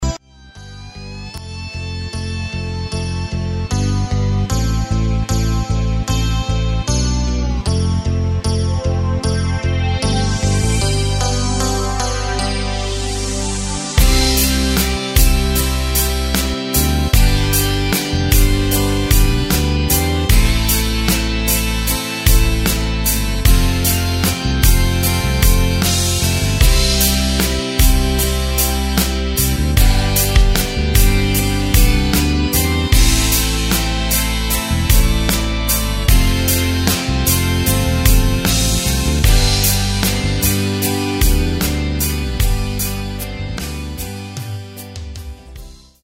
Takt: 4/4 Tempo: 76.00 Tonart: C
Country Song